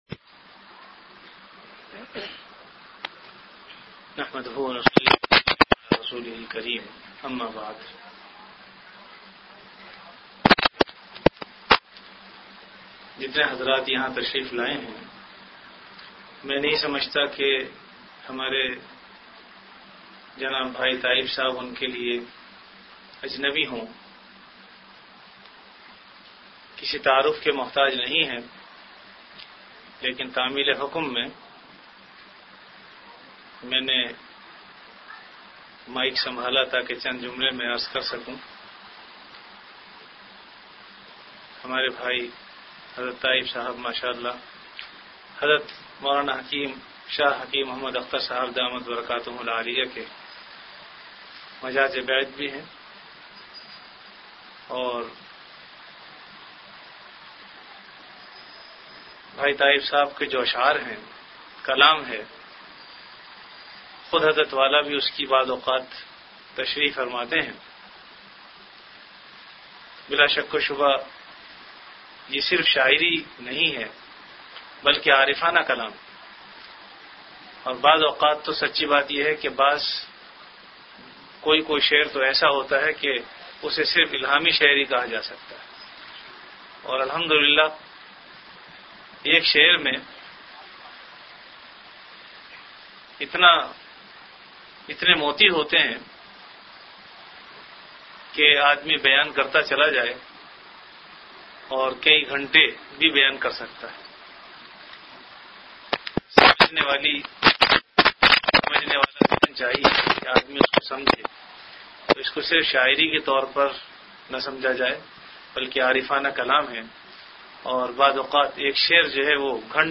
Venue Home Event / Time After Isha Prayer